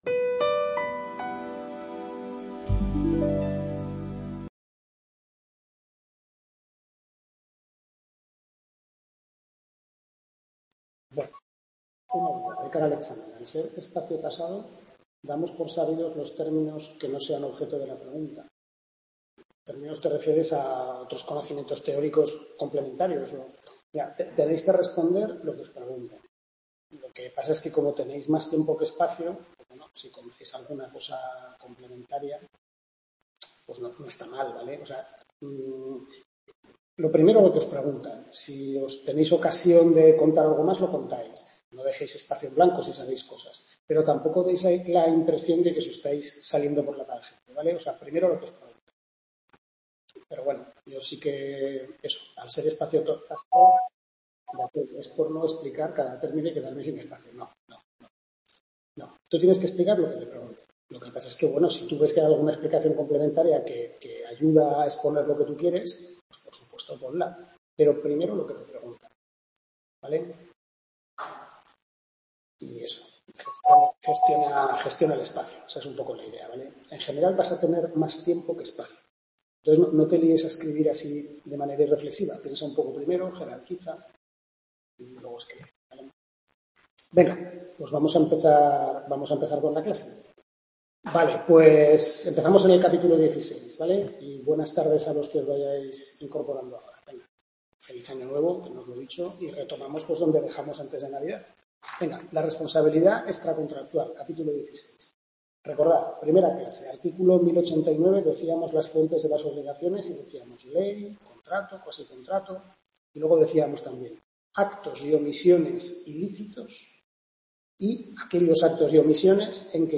Tutoría 6/6 Derecho de Obligaciones